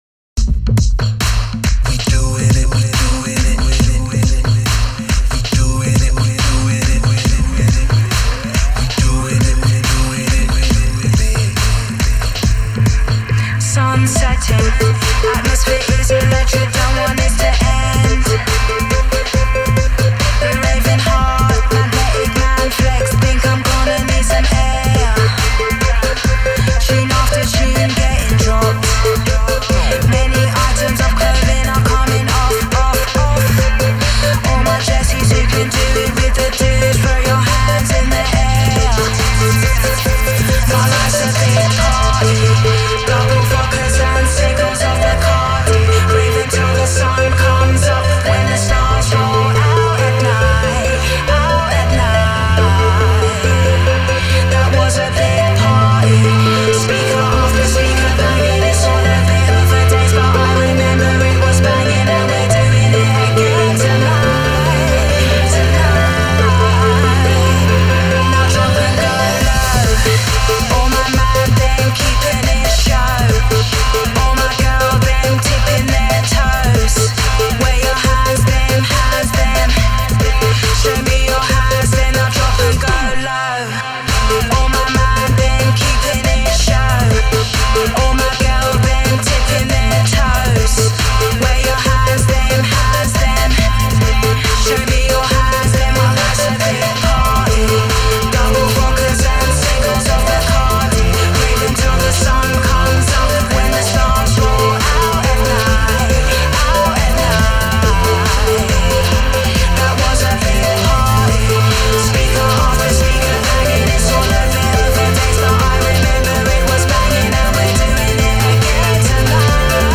sure fire dance floor filler